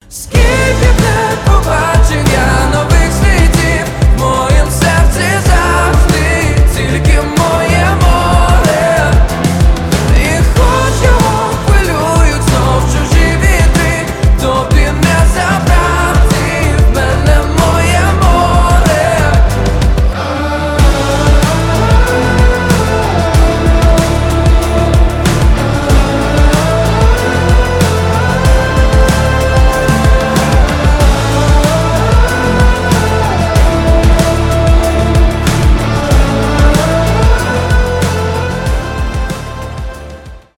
красивый мужской голос , electropop , synth pop